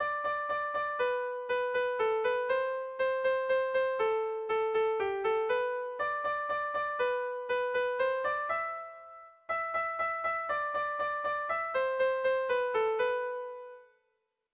Bostekoa, berdinaren moldekoa, 4 puntuz (hg) / Lau puntukoa, berdinaren moldekoa (ip)
ABA2D